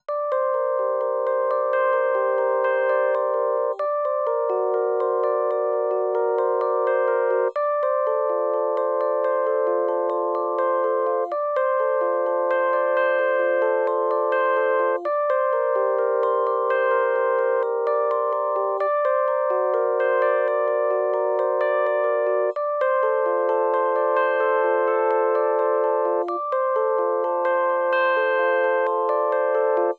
07 rhodes B.wav